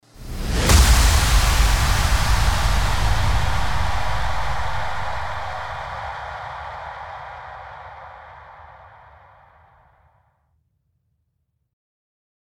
FX-1831-IMPACT
FX-1831-IMPACT.mp3